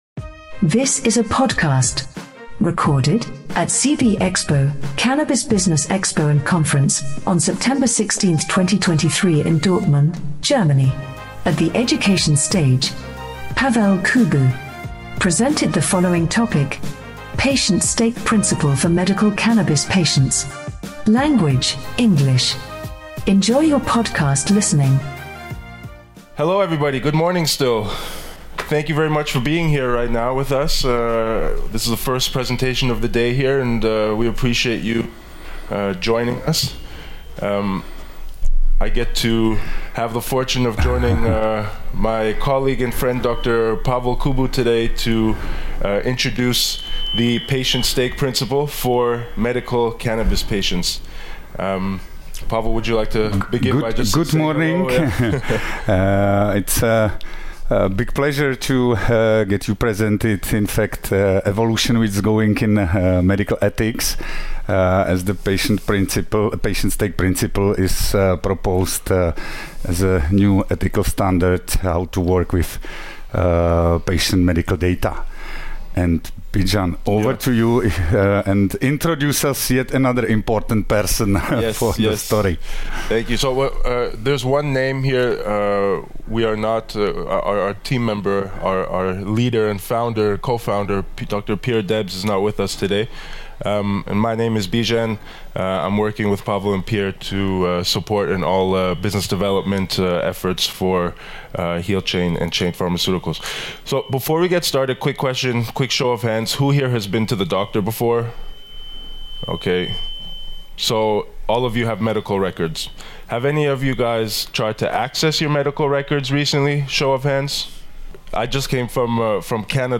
This podcast was recorded as part of the Cannabis Business Expo and Conference 2023 in Drotmund, Germany.